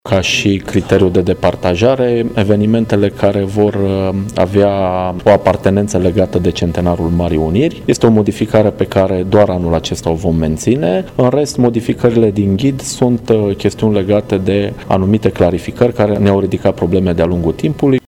Suma destinată proiectelor culturale, cu finanțare de la bugetul local, este de două milioane lei, la fel ca anul trecut – a precizat viceprimarul Costel Mihai, cu prilejul unei conferințe de presă.
Față de anul trecut, a intervenit o modificare importantă, ținându-se cont de toate manifestările culturale care vor avea loc cu ocazia Centenarului Marii Uniri și care vor puncta în plus la evaluarea proiectelor.  Viceprimarul Brașovului, Mihai Costel: